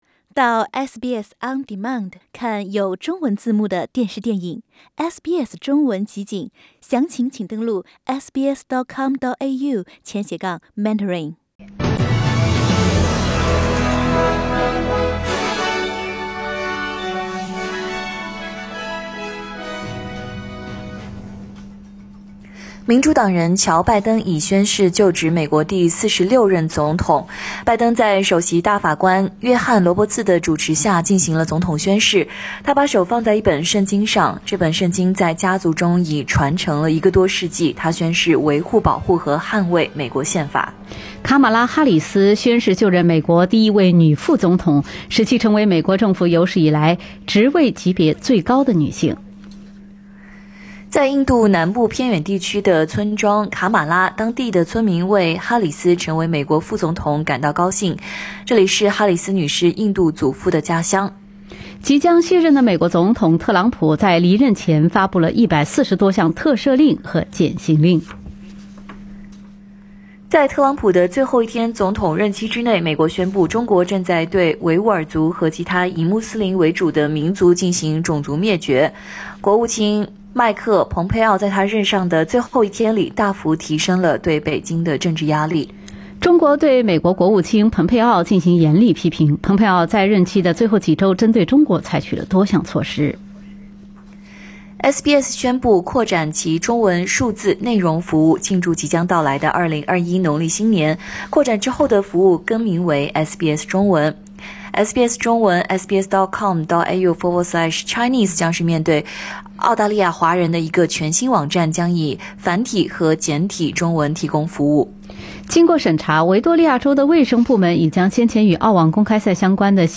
SBS早新闻（1月21日）